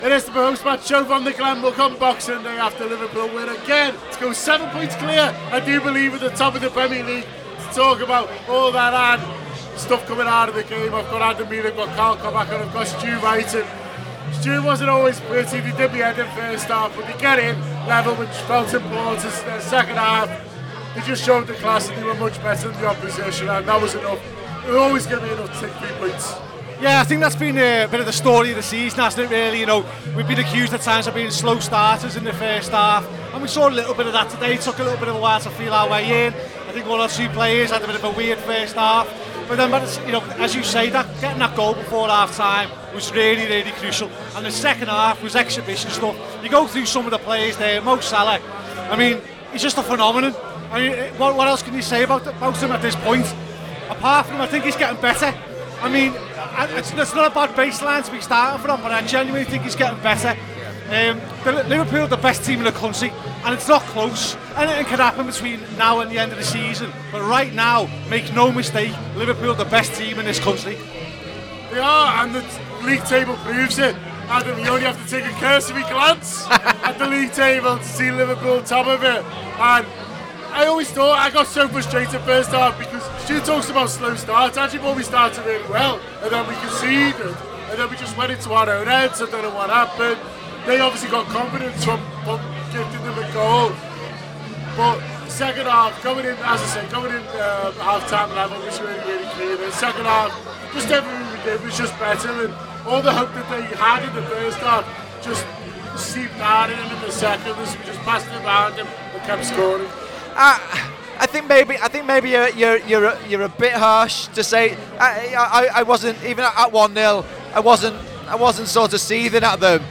The Anfield Wrap’s post-match reaction podcast after Liverpool 3 Leicester City 1 in the Premier League at Anfield.